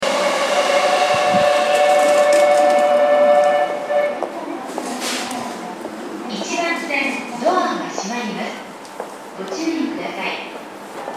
千駄ヶ谷駅　Sendagaya Station ◆スピーカー：ユニペックス箱型
1番線発車ベル
sendagaya1ban.mp3